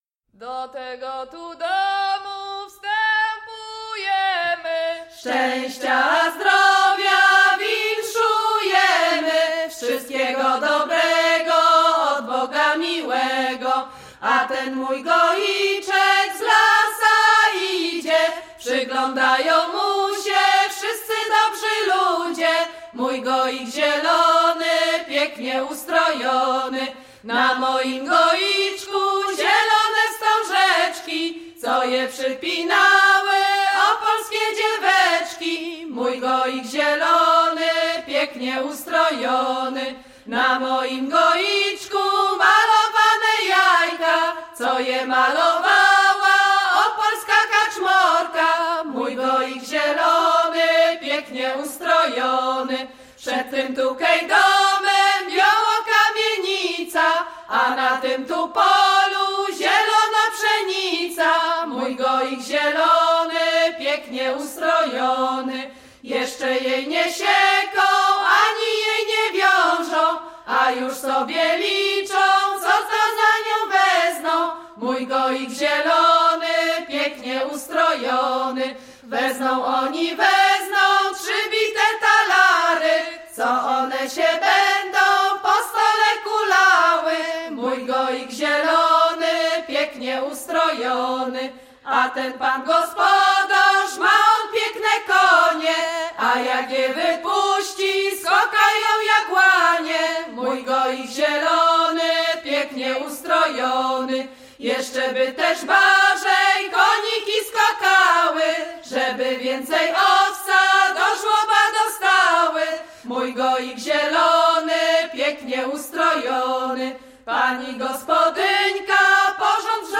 Niezłe Ziółka
Śląsk Opolski